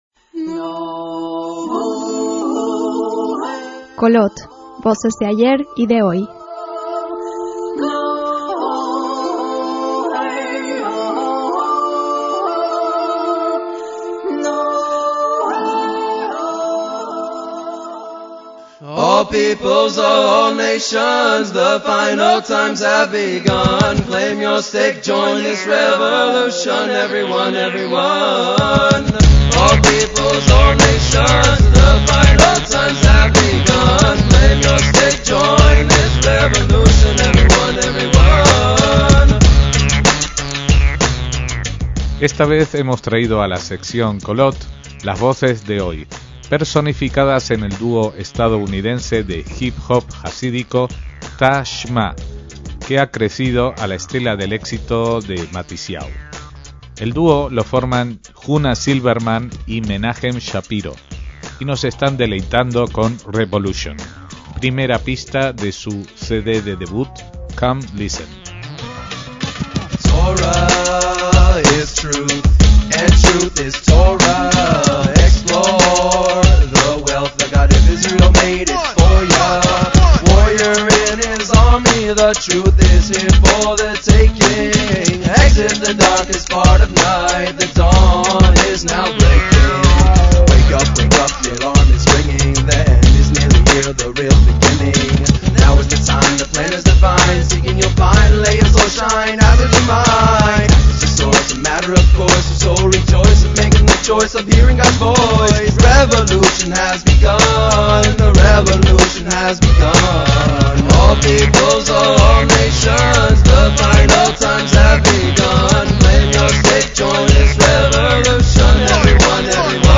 dúo de rap jasídico
clarinetista